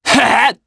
Ezekiel-Vox_Attack1_jp.wav